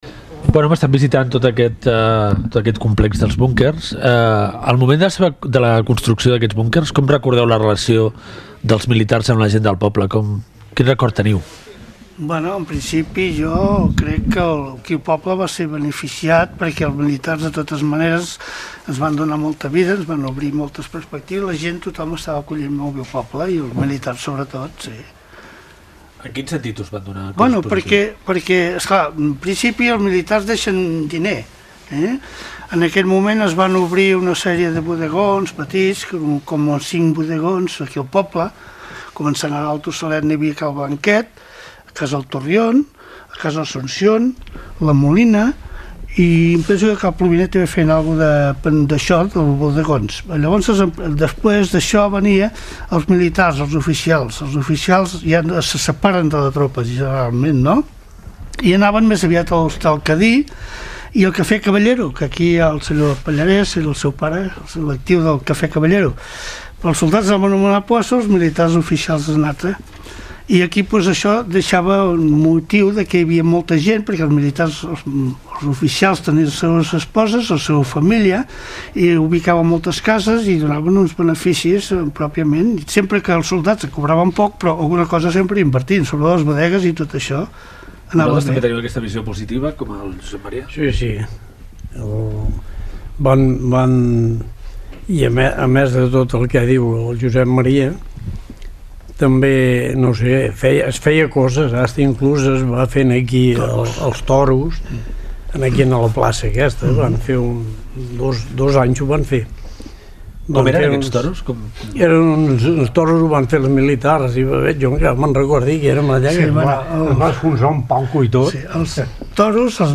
Esment als búnquers del poble i conversa amb veterans sobre la presència de militars al poble de Martinet (Cerdanya).
Divulgació